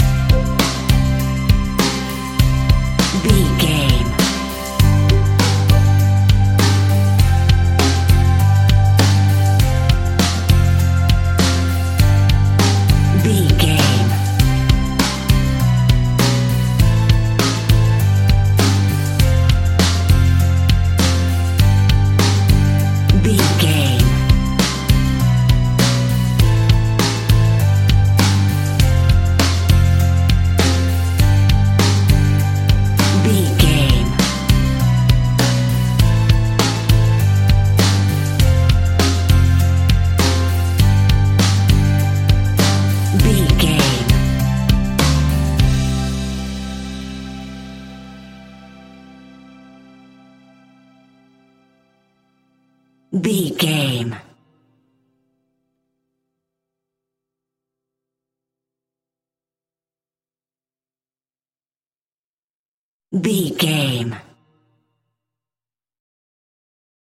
Ionian/Major
calm
happy
smooth
uplifting
electric guitar
bass guitar
drums
pop rock
indie pop
organ